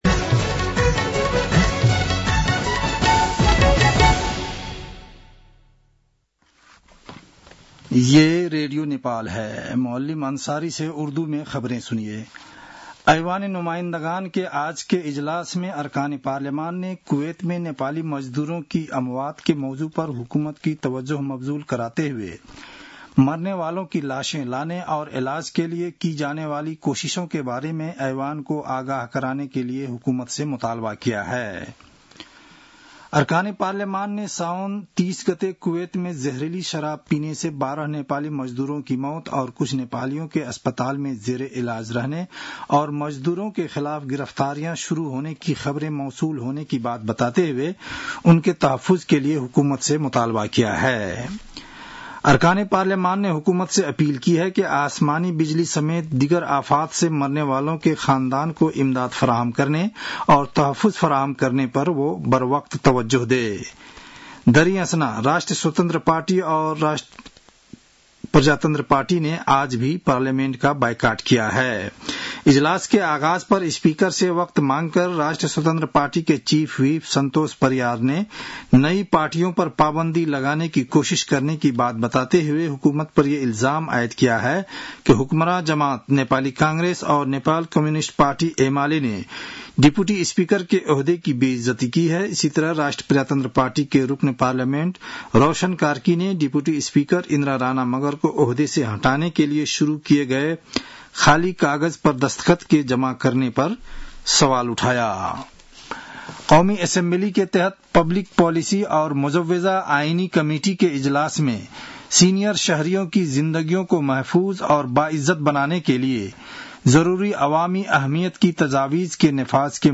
उर्दु भाषामा समाचार : ४ भदौ , २०८२